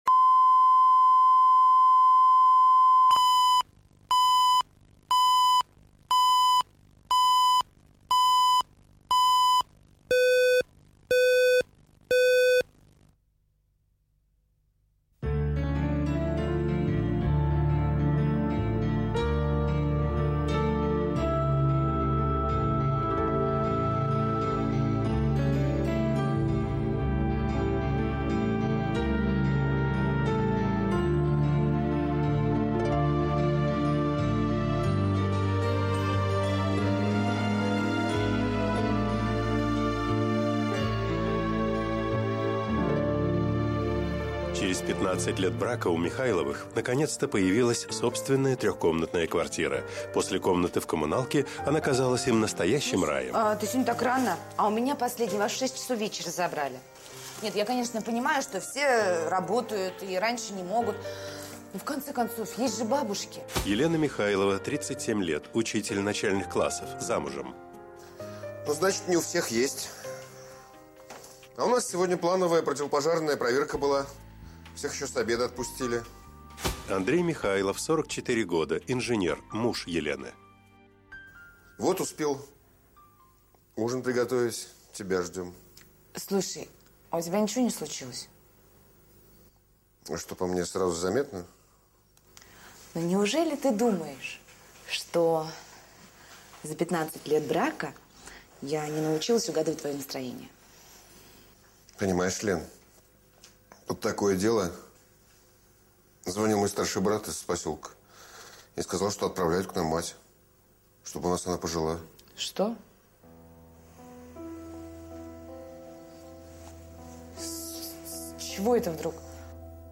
Аудиокнига Чье старичье